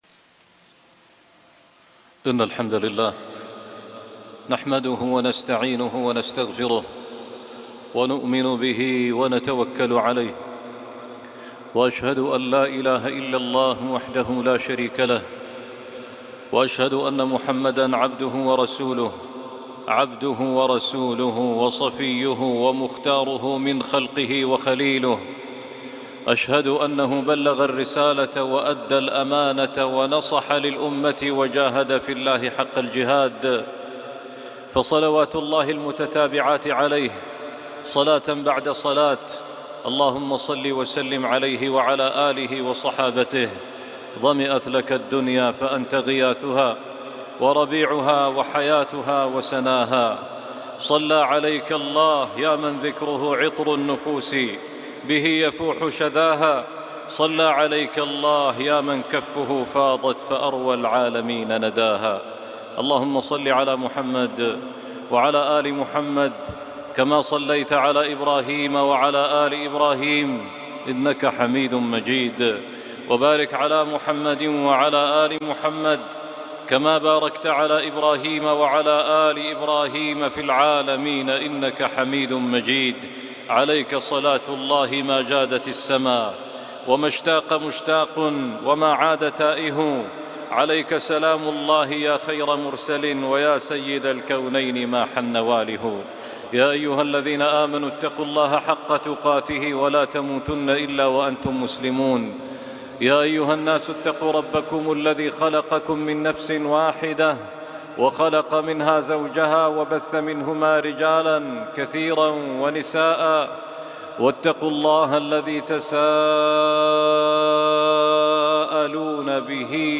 وينشر رحمته - خطبة وصلاة الجمعة